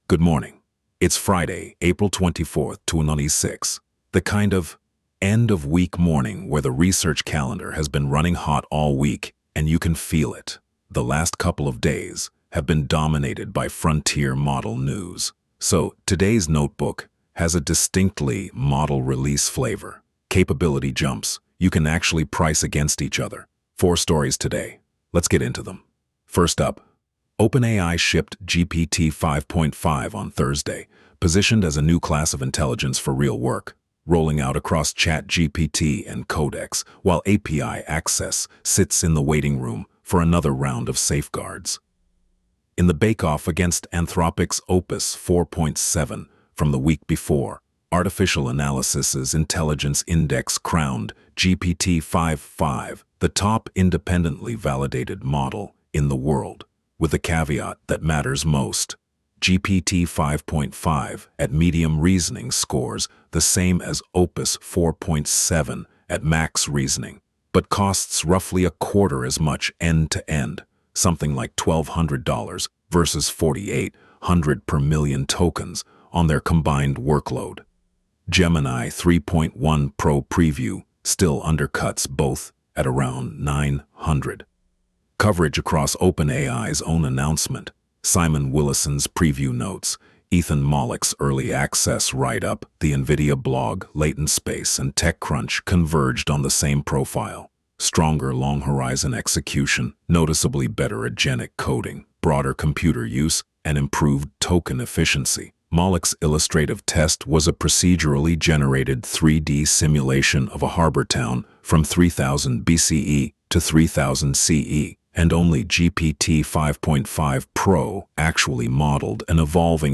Wolf Digest — 2026-04-24 Coverage window: 2026-04-23 03:21 ET → 2026-04-24 03:02 ET ▶ Press play to listen Friday, April 24, 2026 13m 27s · top-4 narrated briefing Subscribe Apple Podcasts Spotify Download MP3